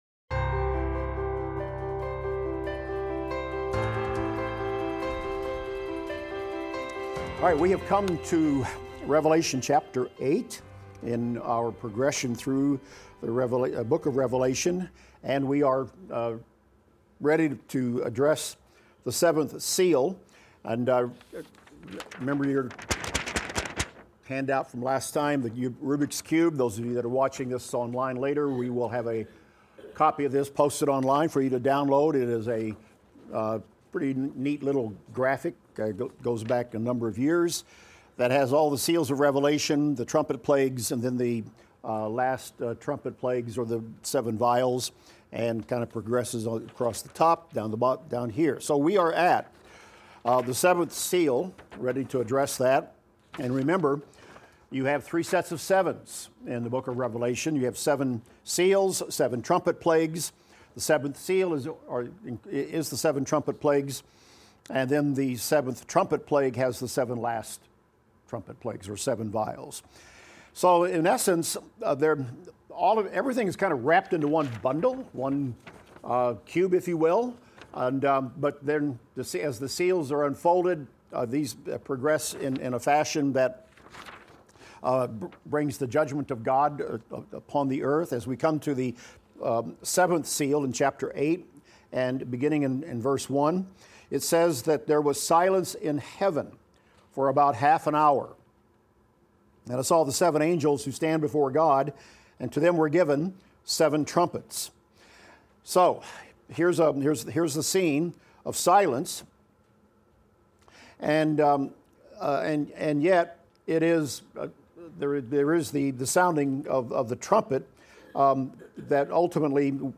Revelation - Lecture 39 - Audio.mp3